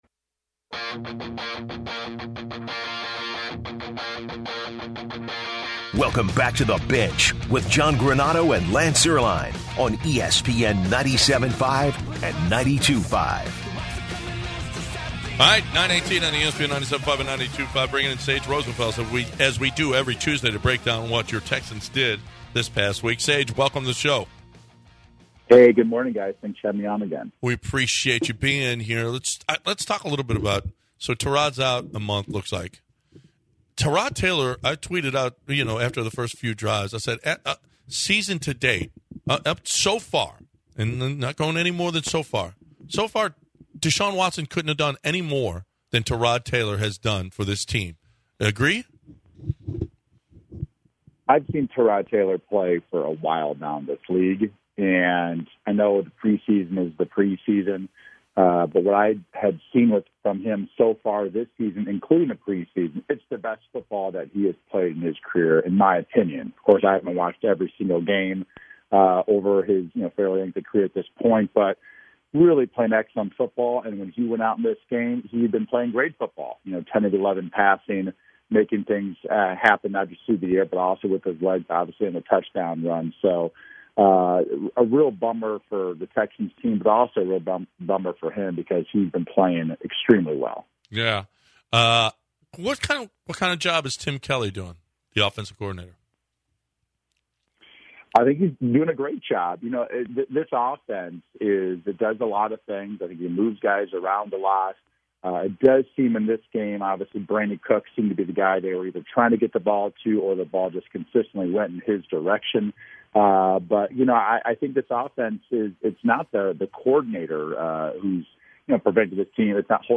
Former Texans QB Sage Rosenfels joins The Bench to discuss the Texans season so far and their quarterback situation with Tyrod Taylor out.